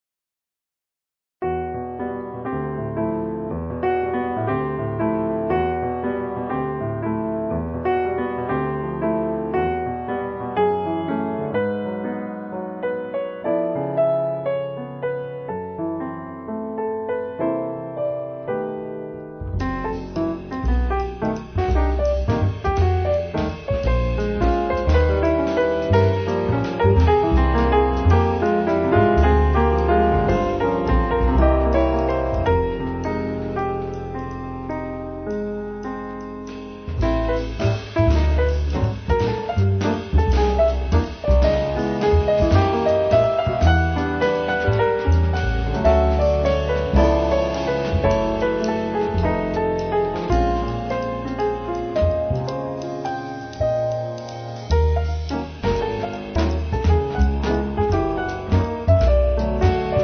pianoforte
basso
batteria
un lavoro con una sonorità compatta, equilibrata